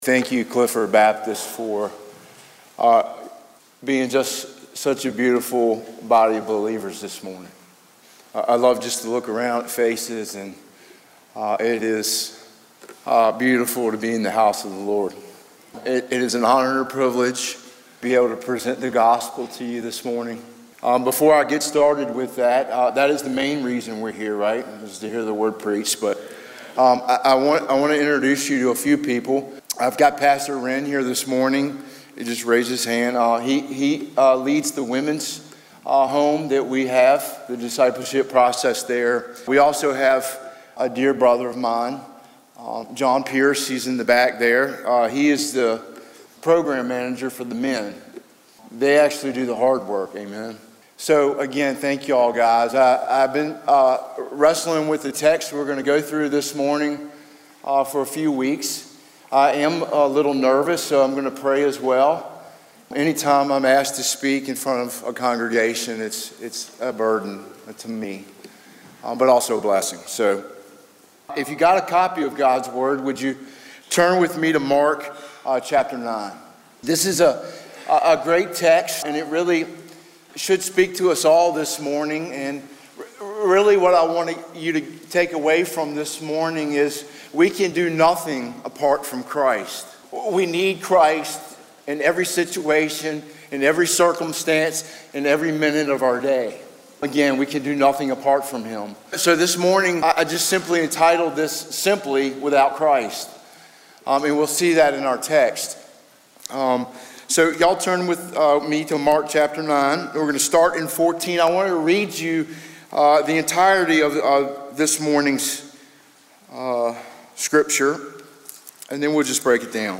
Mark 9:14-29, "Without Christ", Guest Speaker